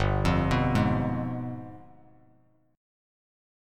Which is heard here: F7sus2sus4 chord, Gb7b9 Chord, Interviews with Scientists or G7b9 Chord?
G7b9 Chord